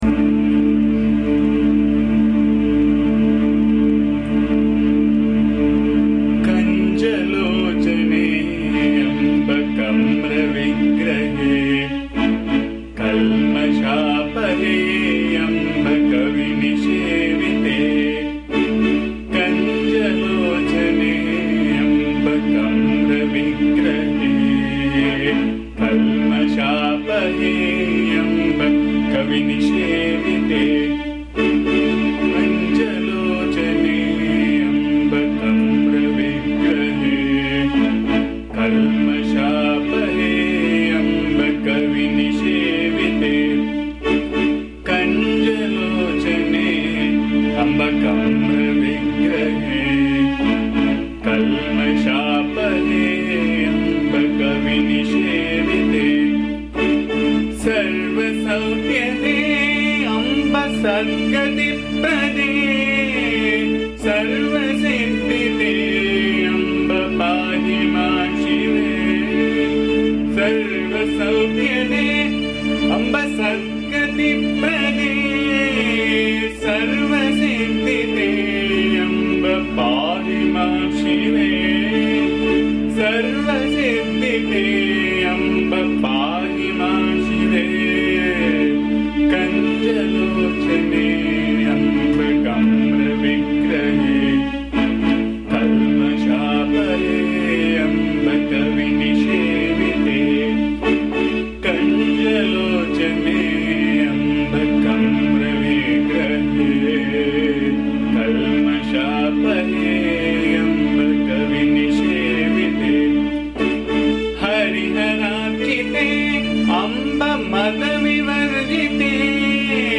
This song is set in the Raga Kedaram. This song is a simple song with rhythmic and beautiful tune which can make any person sleep. The song is in the form of praising the Goddess Devi (Tripurasundari). The song has been recorded in my voice which can be found here. Please bear the noise, disturbance and awful singing as am not a singer.
AMMA's bhajan song